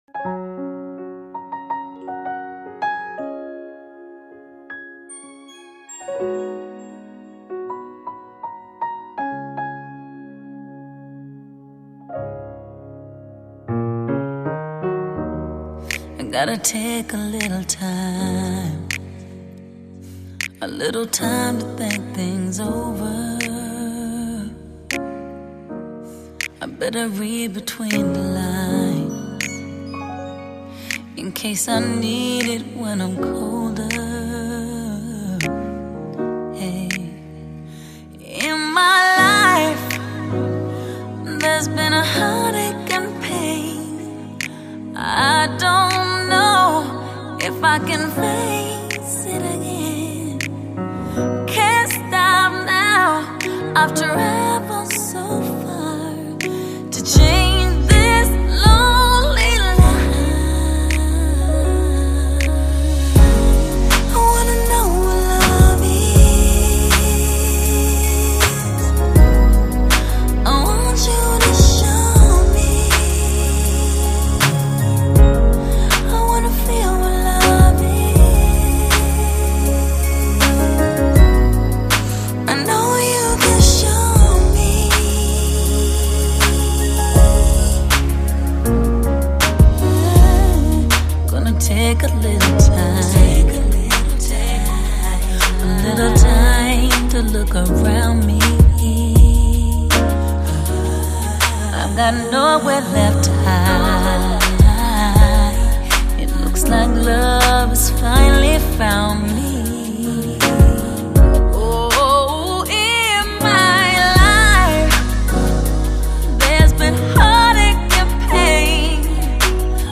Surround7.1三维环绕HI—FI最经典人声高临场感音效。